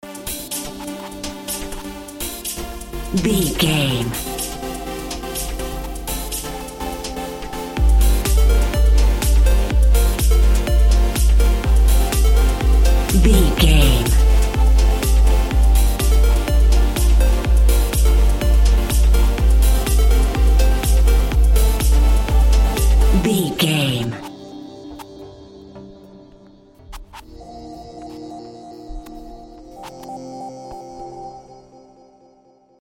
Aeolian/Minor
groovy
uplifting
driving
energetic
synthesiser
drum machine
house
techno
synth bass
upbeat